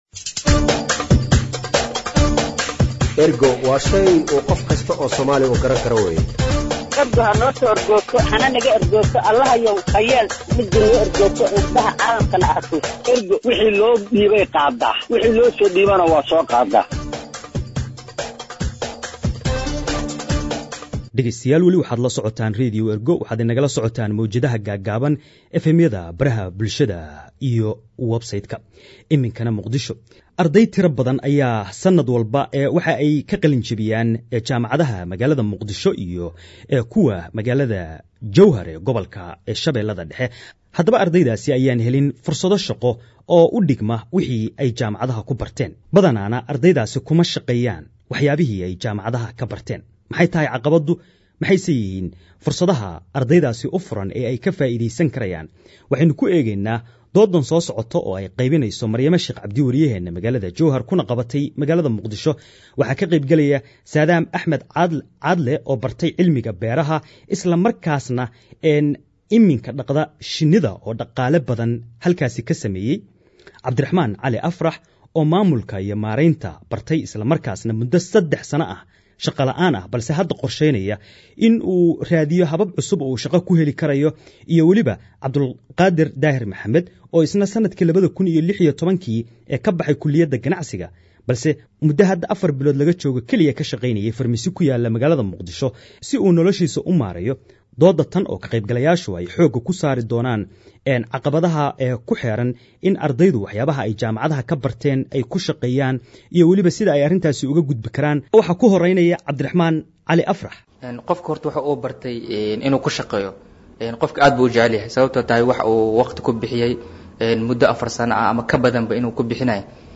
Dood ku saabsan fursadaha iyo caqabadaha ardayda jaamacadaha dhameeya